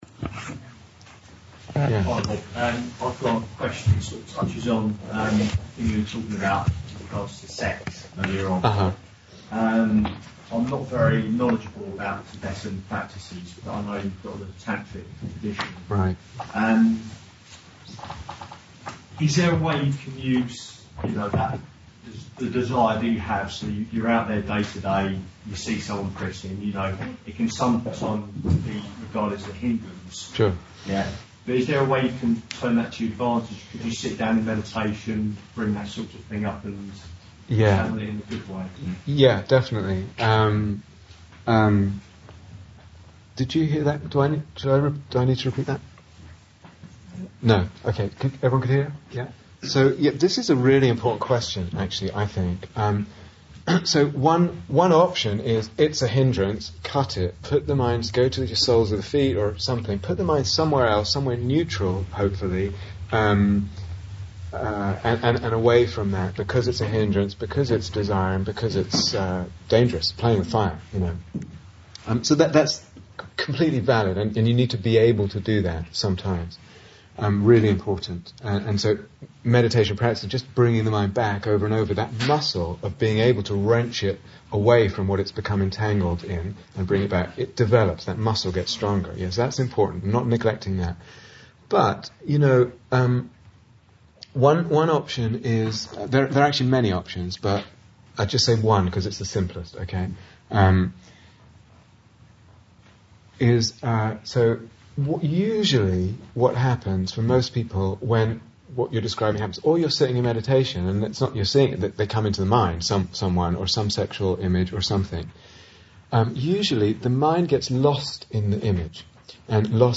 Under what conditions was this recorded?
Q&A (am) Download 0:00:00 --:-- Date 7th December 2014 Retreat/Series Day Retreat, London Insight 2014 Transcription Q1: working with sexual energy in meditation Yogi: I've got a question.